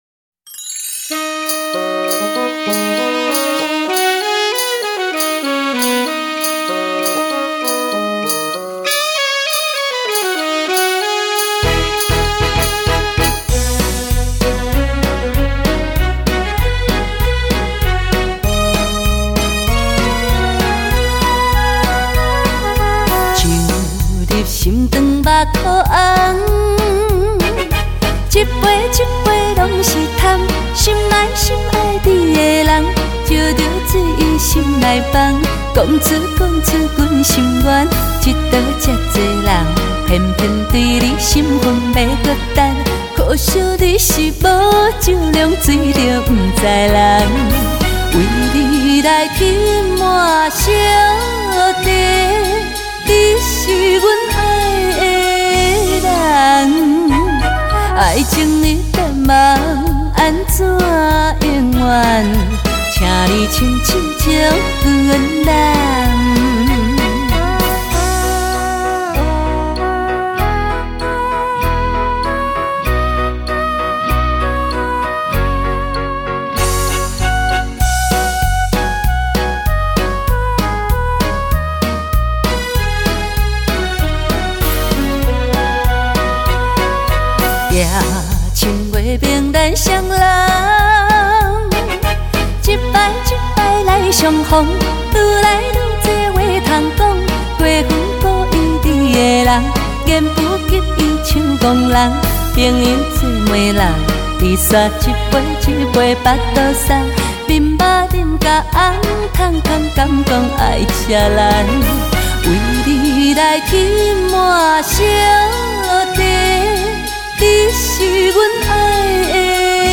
优美抒情